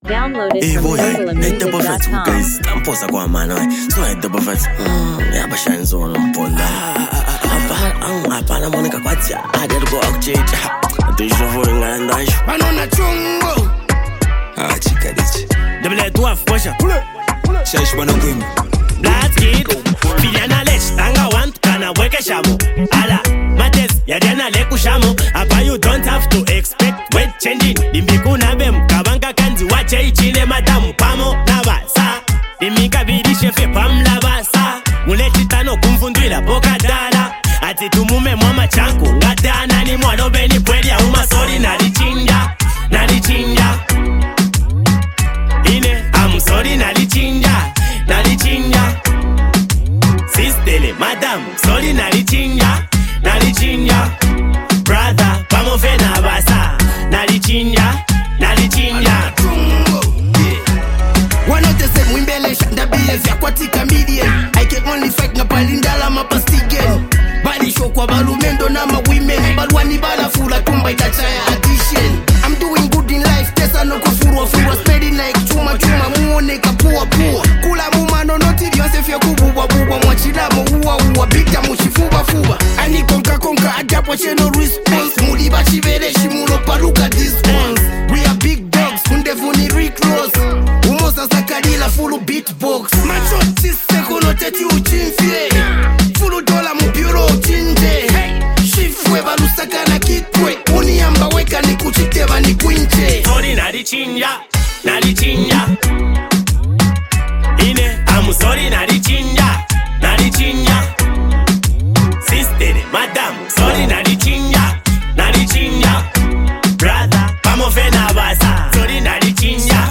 catchy hooks and smooth vocal delivery